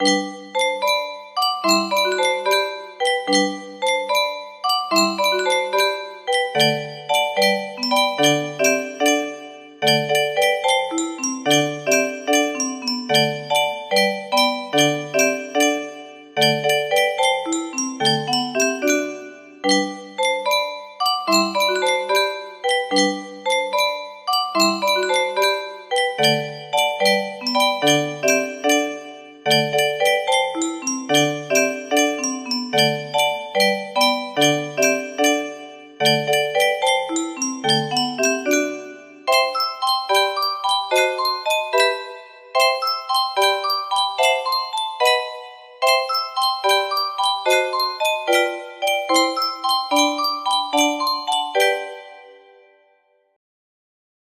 Anónimo - Danza Indígena Nº4 music box melody
Danza indígena de Jalisco, arreglada por Rubén M. Campos